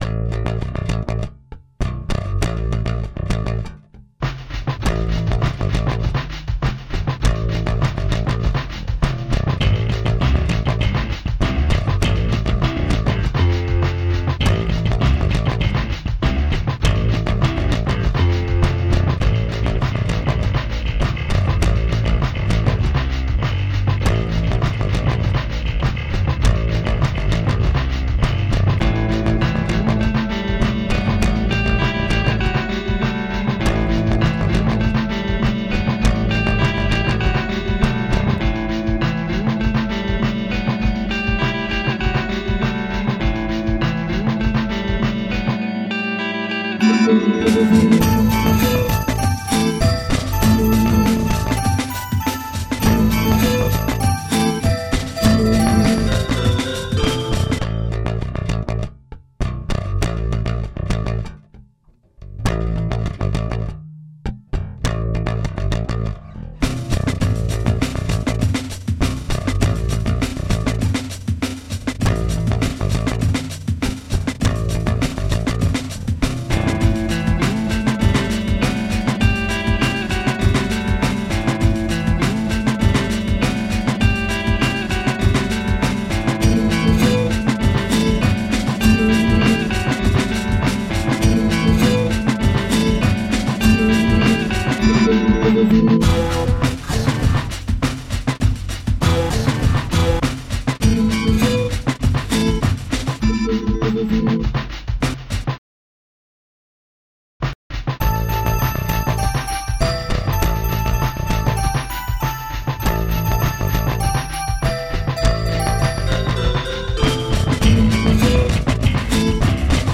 Instrumental - Lyrics